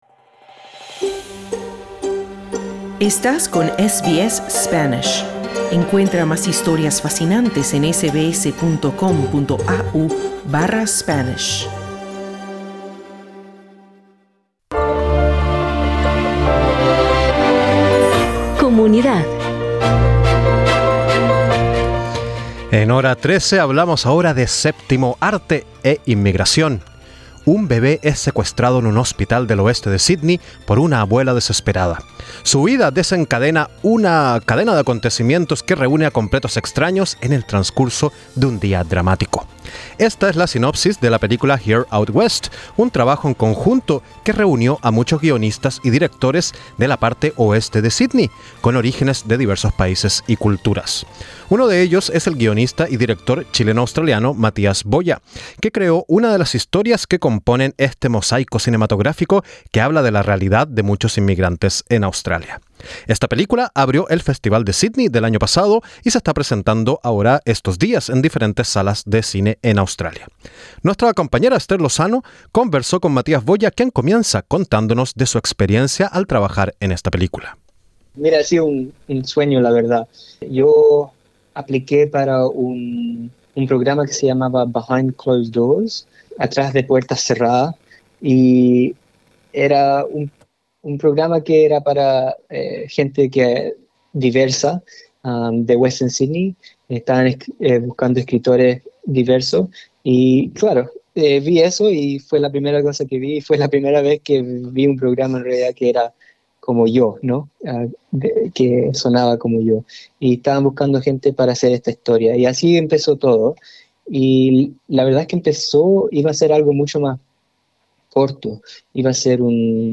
Esta película reunió a artistas con diferentes orígenes culturales y lingüísticos afincados en Australia. Si quieres escuchar la entrevista completa presiona la imagen principal.